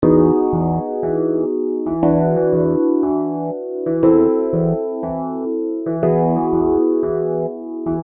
描述：罗兹钢琴的第二部分
Tag: 120 bpm Jazz Loops Piano Loops 1.35 MB wav Key : Unknown